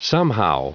Prononciation du mot somehow en anglais (fichier audio)
Prononciation du mot : somehow